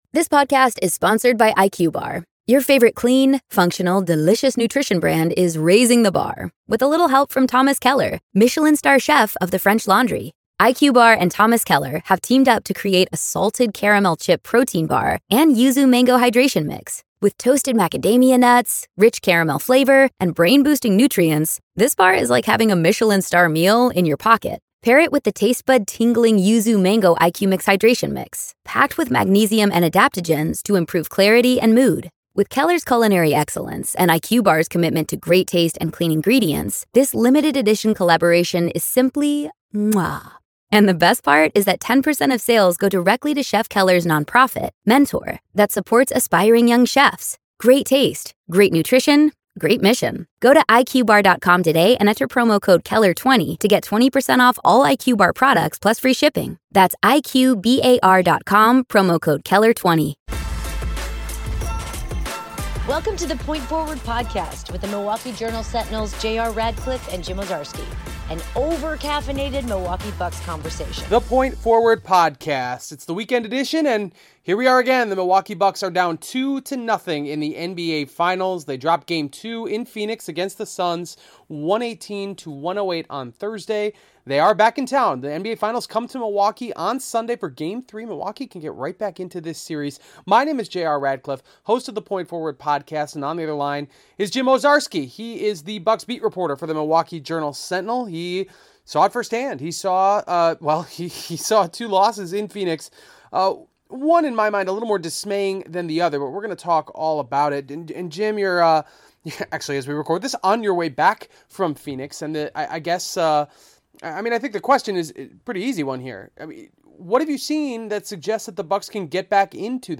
Music intro